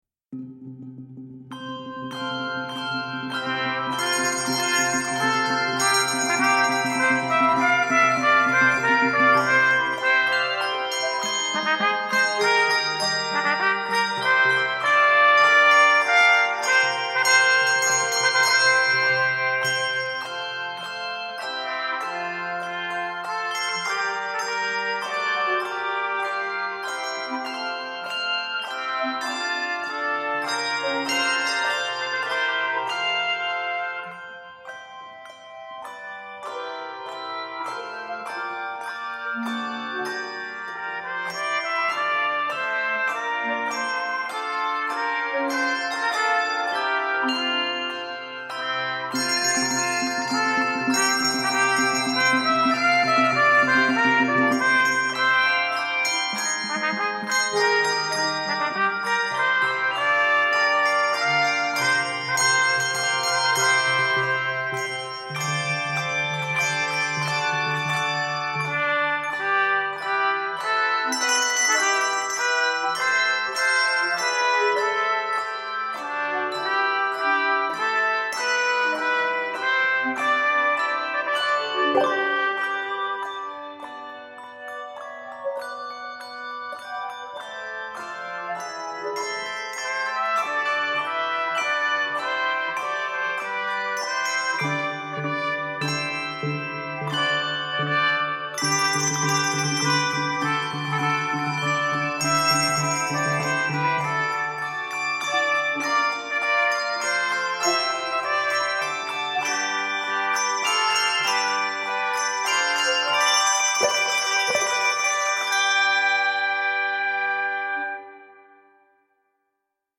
Keys of F Major and G Major.